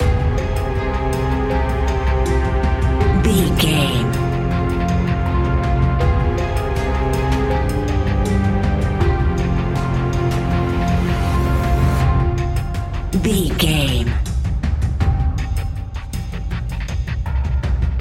Aeolian/Minor
ominous
dark
haunting
eerie
strings
drums
percussion
synthesiser
electric piano
horror music